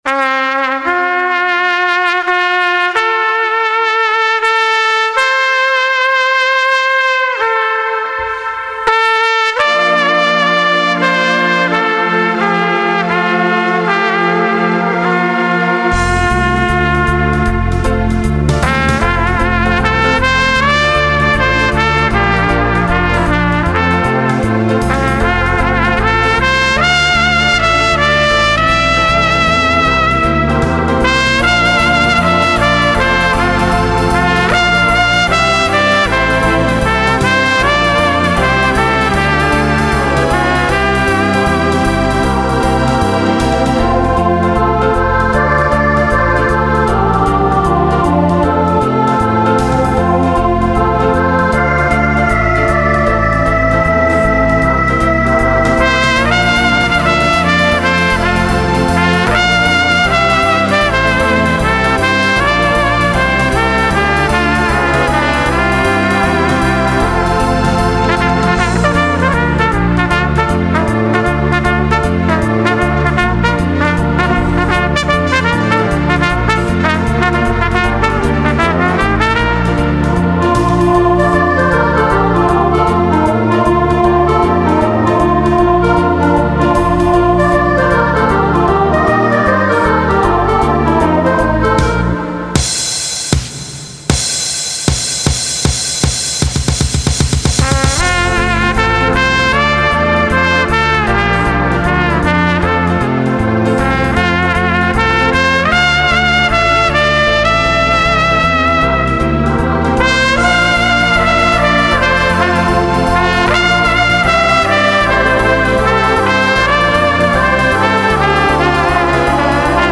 印象に残るシンプルなメロディが気に入ったので、やってみることにしました。
ちょっと録音レベルが高すぎたようで、歪んでいますが・・まあ、それも1960年代の雰囲気ということでご勘弁・・